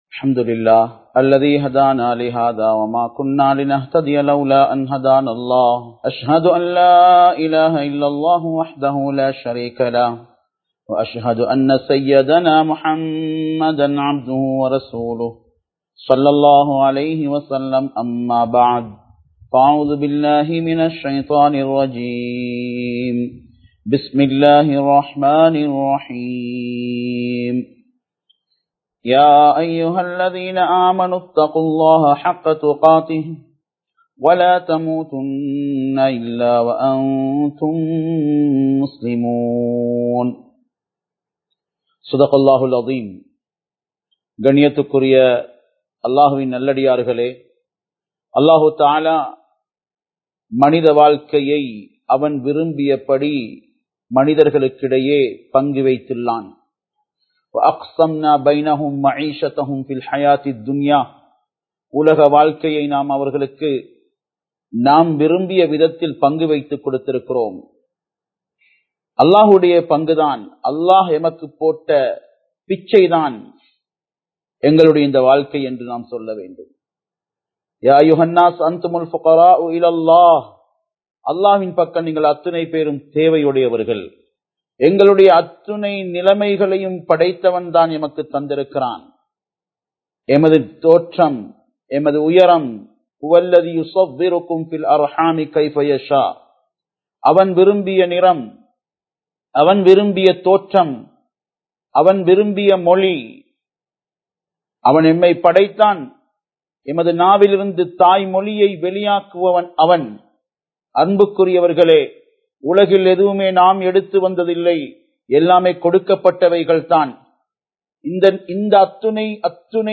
மனிதர்களை மதியுங்கள் (Respect the Human being) | Audio Bayans | All Ceylon Muslim Youth Community | Addalaichenai
Live Stream